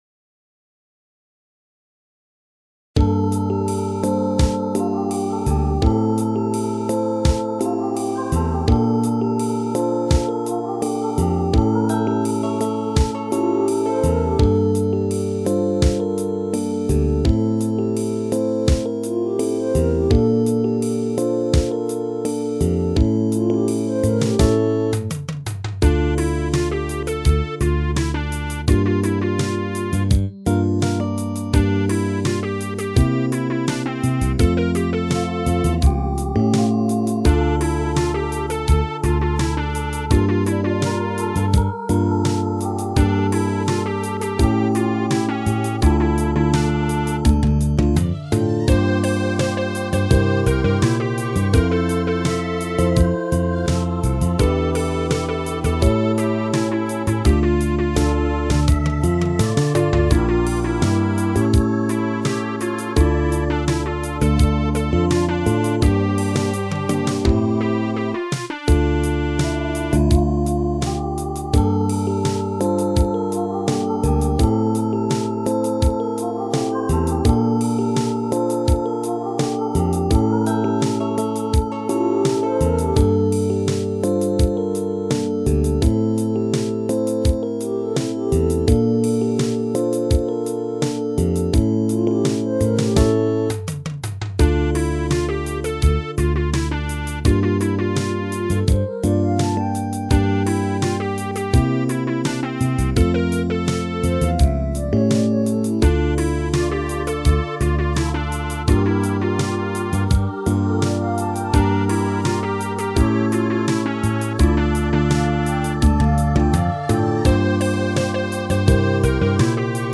その分もう一枚のスキャットはかなりリアルになっていると思う。